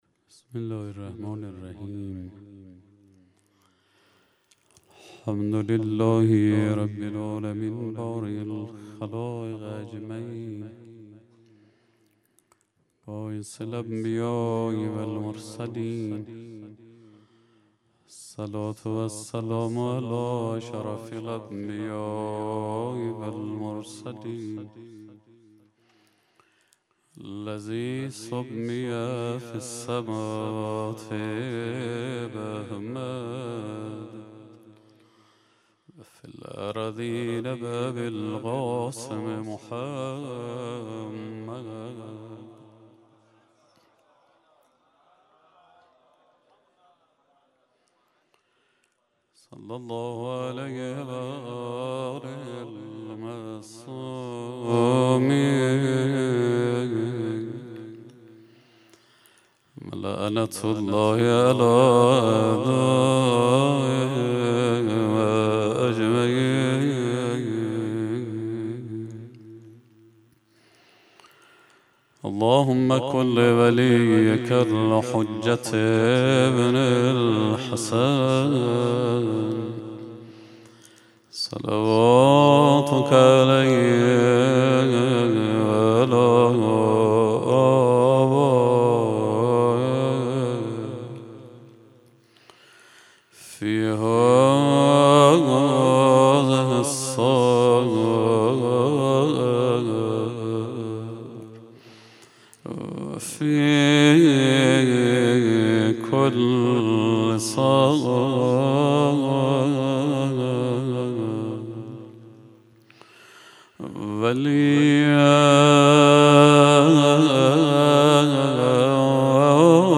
17 اردیبهشت 98 - بیت الرقیه - روزه رمضان
سخنرانی